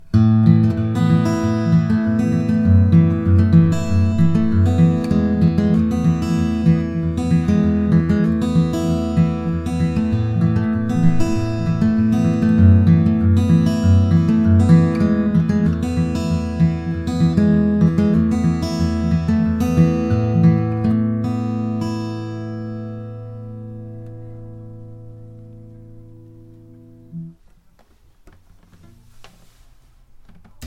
He made under fixed conditions samples of each guitar.
Picking 1